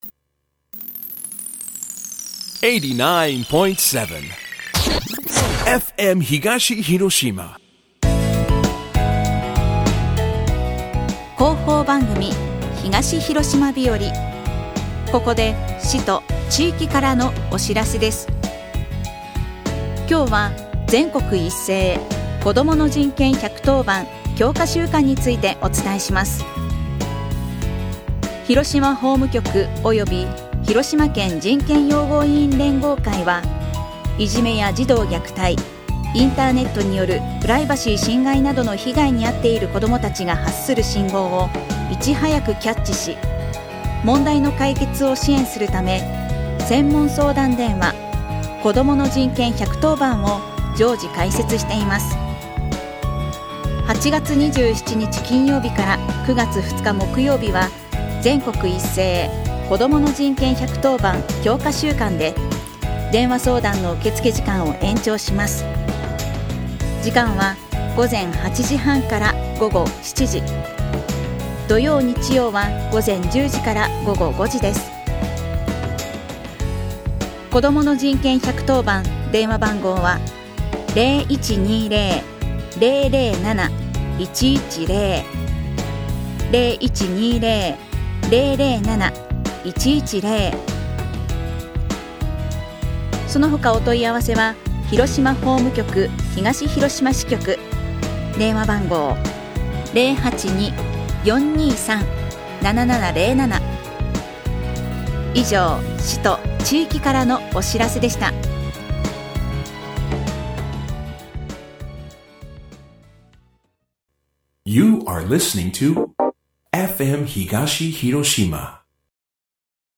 2021年8月27日にＦＭ東広島で朝放送した広報番組「東広島日和」です。今日は「全国一斉子供の人権１１０番強化週間」についてです。